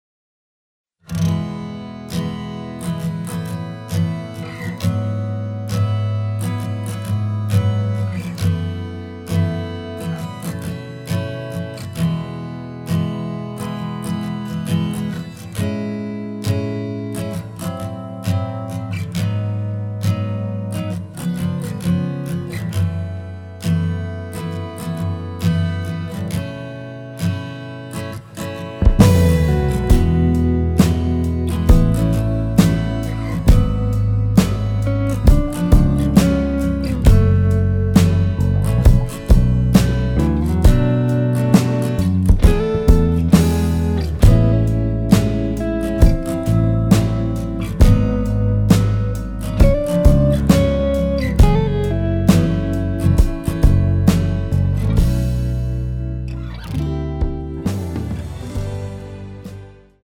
” [공식 음원 MR] 입니다.
앞부분30초, 뒷부분30초씩 편집해서 올려 드리고 있습니다.
중간에 음이 끈어지고 다시 나오는 이유는
위처럼 미리듣기를 만들어서 그렇습니다.